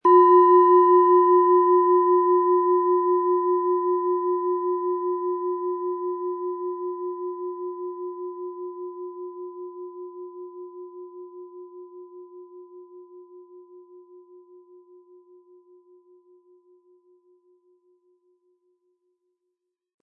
Planetenton 1
Planetenschale®
Mit dem beigelegten Klöppel können Sie je nach Anschlagstärke dominantere oder sanftere Klänge erzeugen.